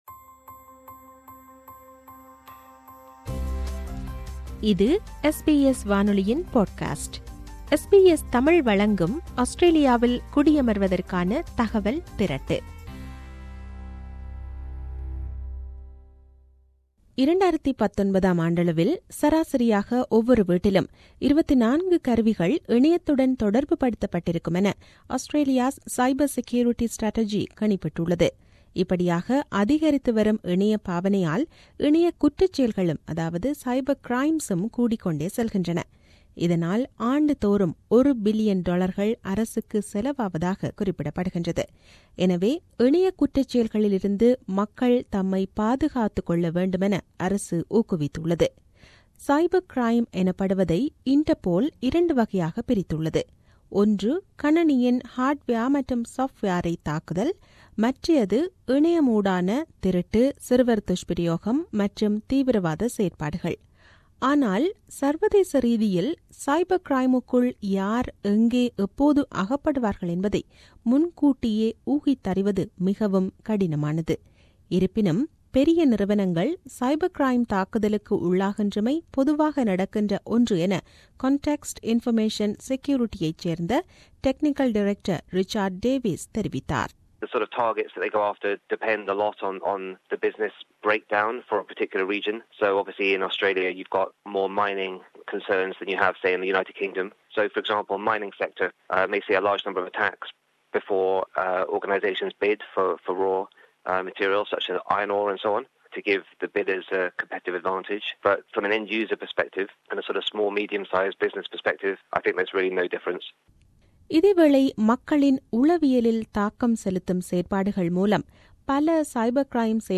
விவரணம்